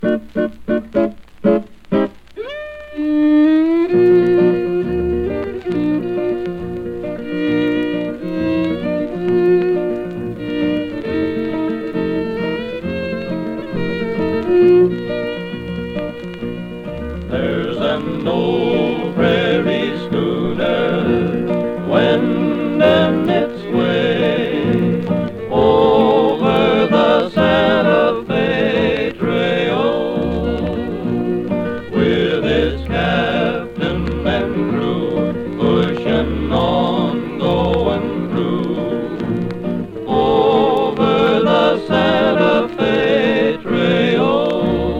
カントリーやウェスタンなそのものずばりの出立ちで、ギター奏者のカールとフィドル奏者のヒューの兄弟デュオ。
※元音源に準ずるプチプチ音有り
Country, Western Swing, Jazz　Germany　12inchレコード　33rpm　Mono